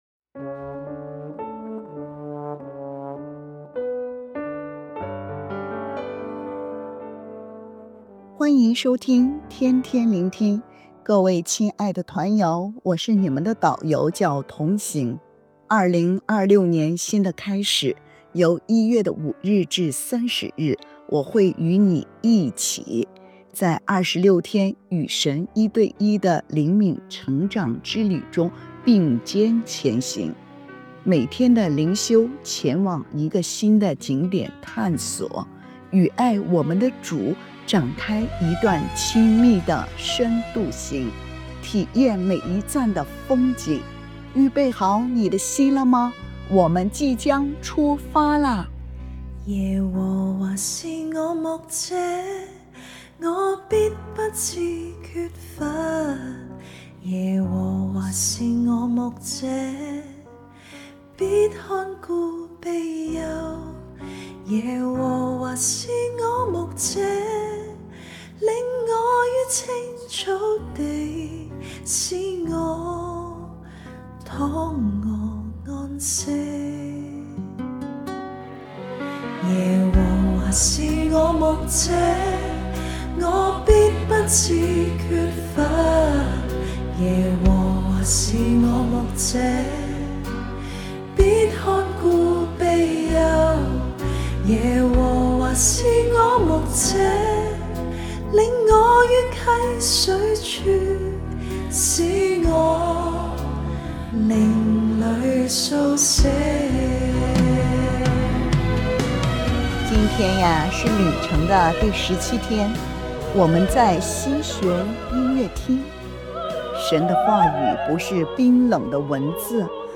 🎶靈修詩歌：《耶和華是我牧者》即興創作Demo